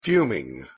pronunciation_en_fuming.mp3